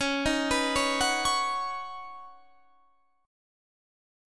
C#7sus2sus4 chord